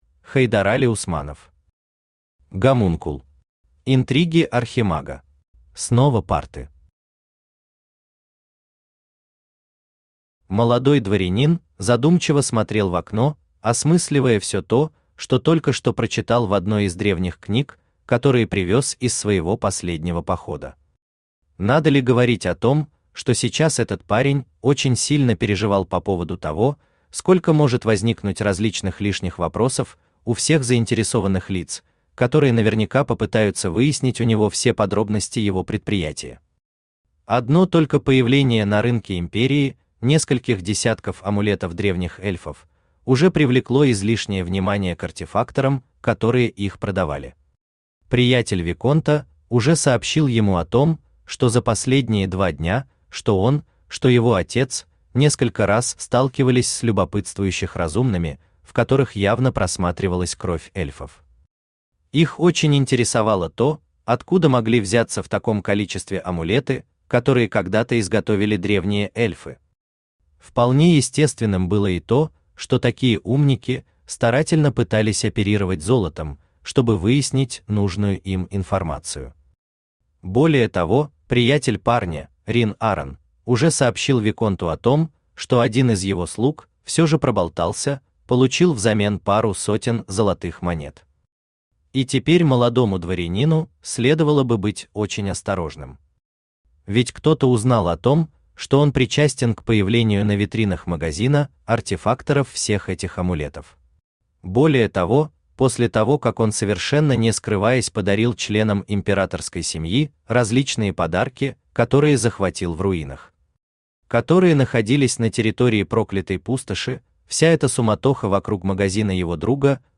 Аудиокнига Гомункул. Интриги Архимага | Библиотека аудиокниг
Интриги Архимага Автор Хайдарали Усманов Читает аудиокнигу Авточтец ЛитРес.